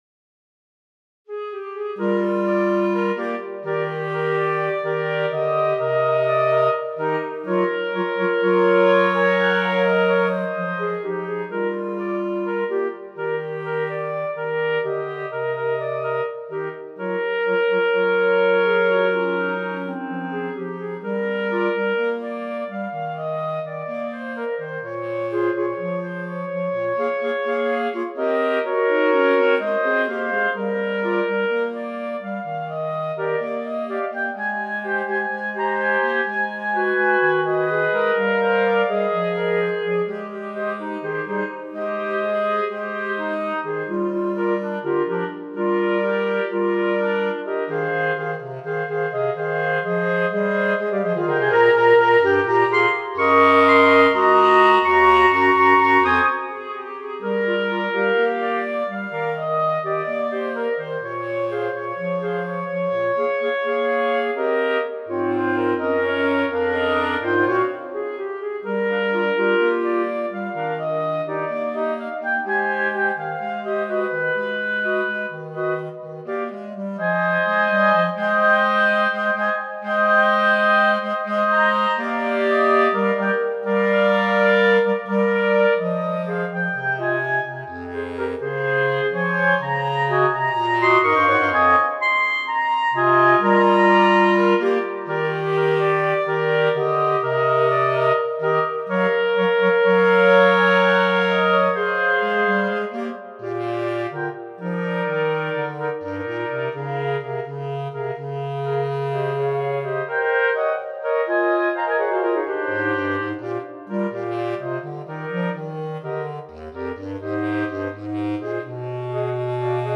– Clarinet Quartet Edition